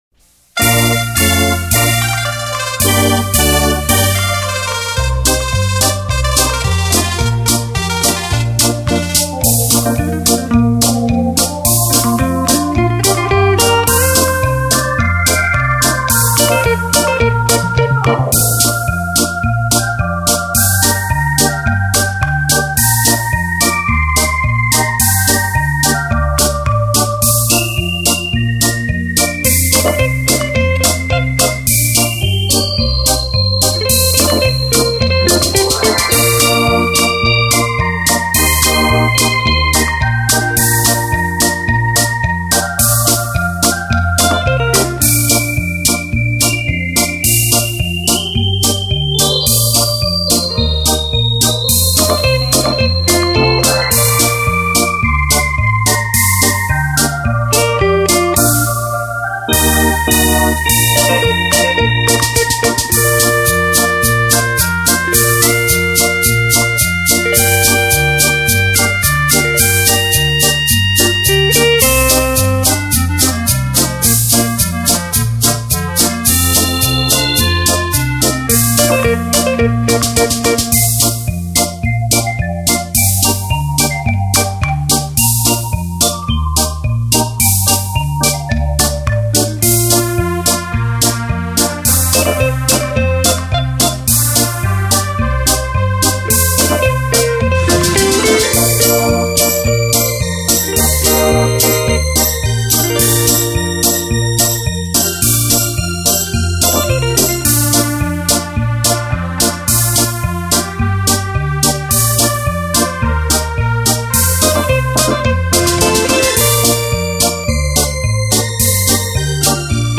专辑格式：DTS-CD-5.1声道
360度声音动态 超立体环绕音场
近百种真实自然声音．最佳环境音响测试片